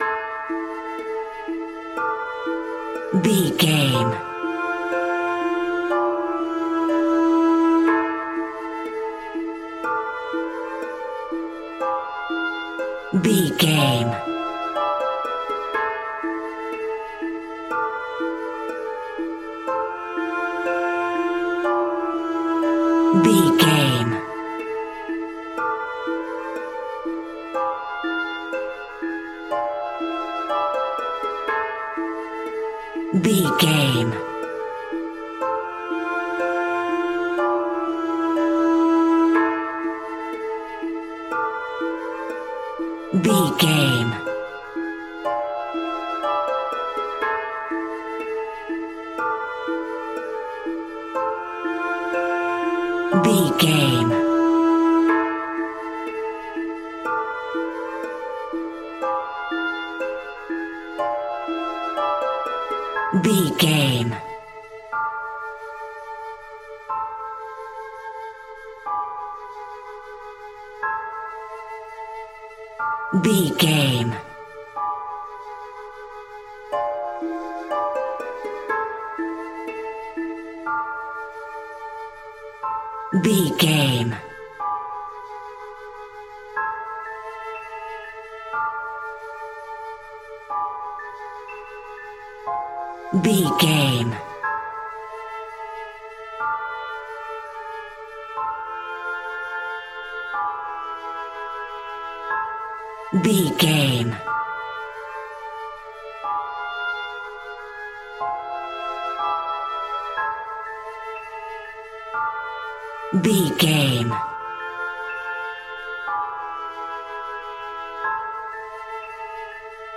Aeolian/Minor
ethnic percussion
ethnic strings